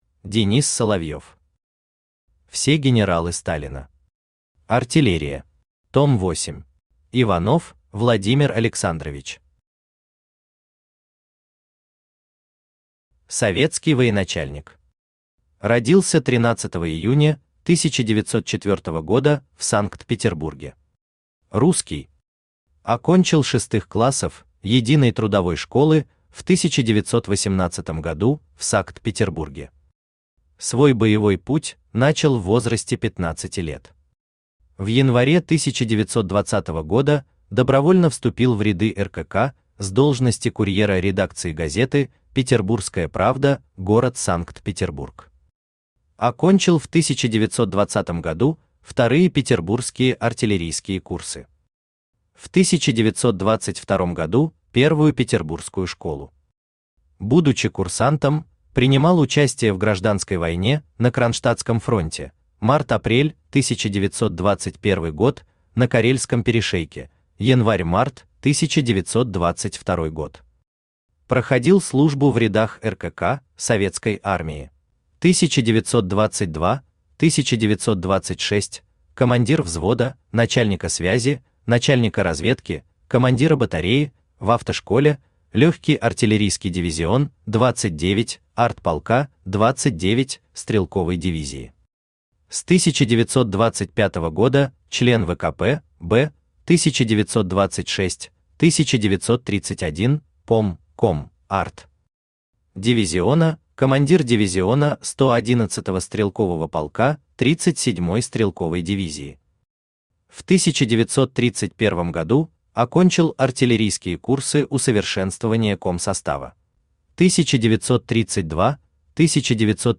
Аудиокнига Все генералы Сталина. Артиллерия. Том 8 | Библиотека аудиокниг
Том 8 Автор Денис Соловьев Читает аудиокнигу Авточтец ЛитРес.